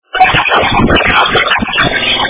какаято девка чето поет на испанском или мексиканском )))